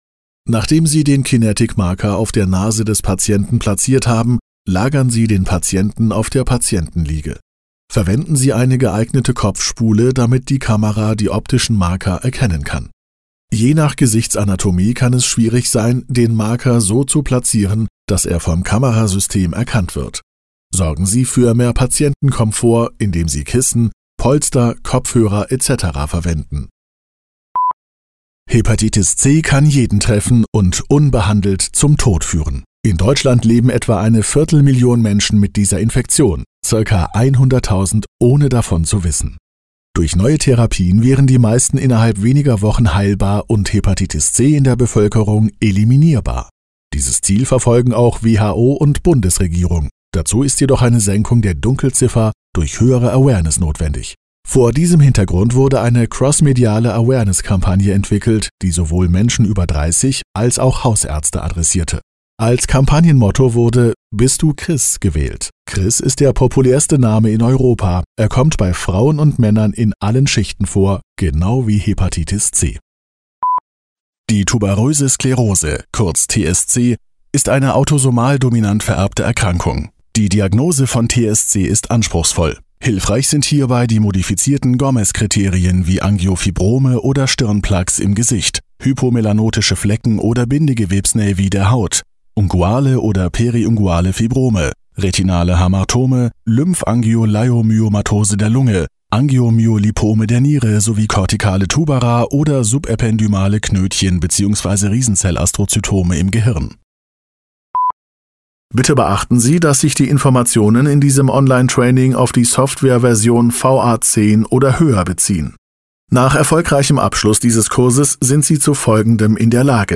Male
Approachable, Assured, Bright, Character, Confident, Conversational, Cool, Corporate, Deep, Energetic, Engaging, Friendly, Funny, Natural, Sarcastic, Smooth, Soft, Upbeat, Versatile, Warm
Microphone: Neumann TLM 103